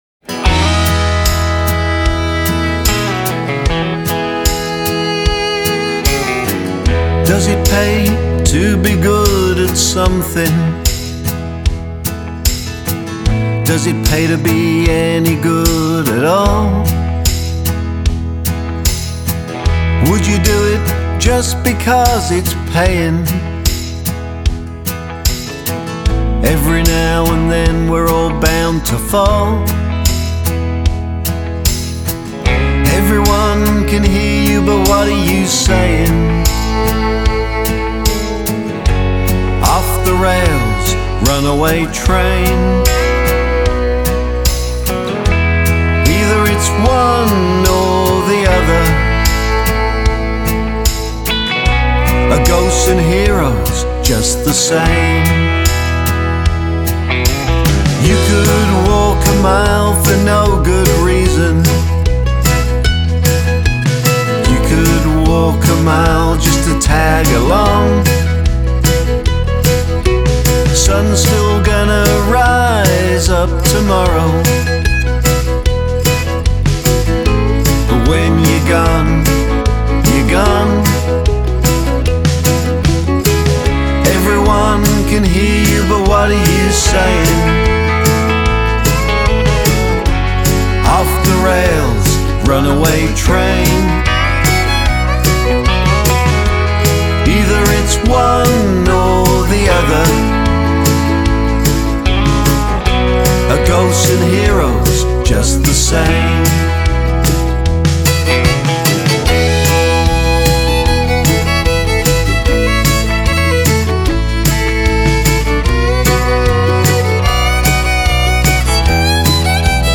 Single Release
The answer lies in this beautifully crafted country track